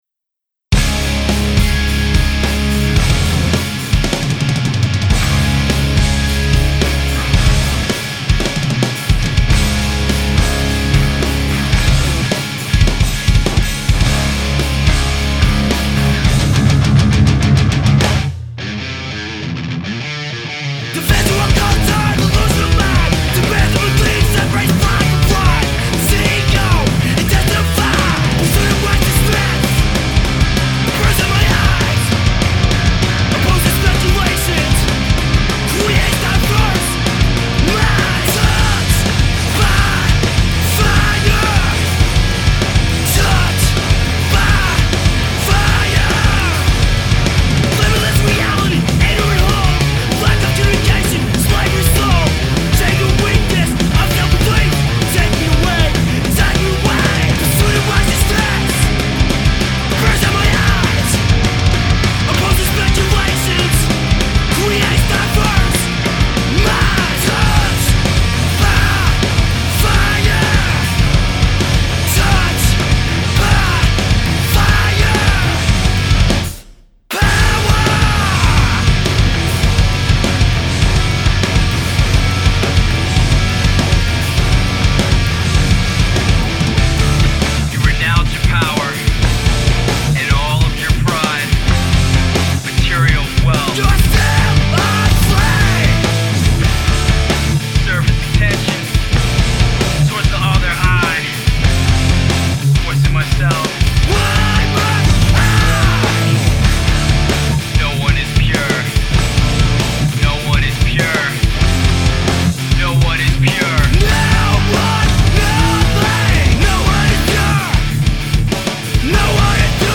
clever vocal flow